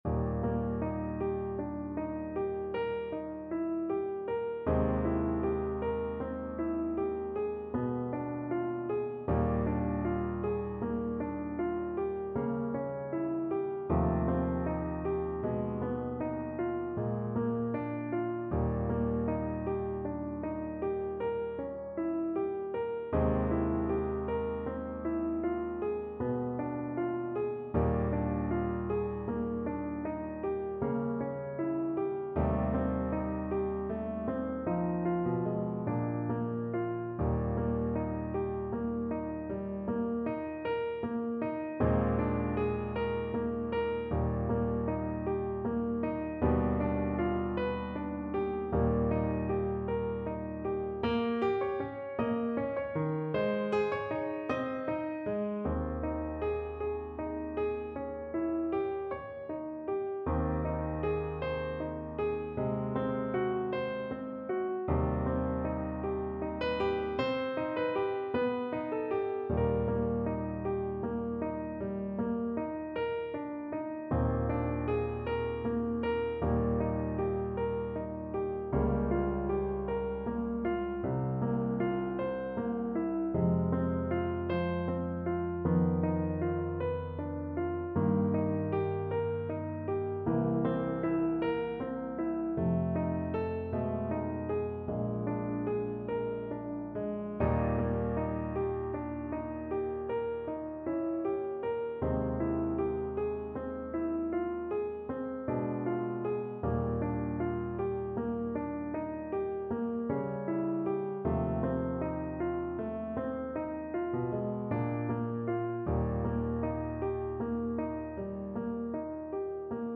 4/4 (View more 4/4 Music)
Adagio sostenuto ( = 52)
Classical (View more Classical Oboe Music)